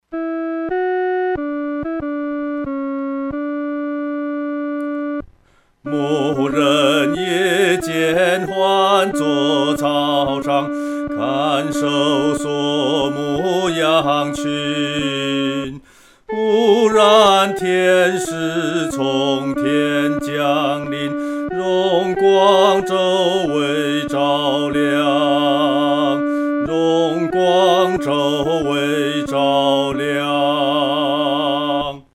独唱（第二声）
牧人闻信-独唱（第二声）.mp3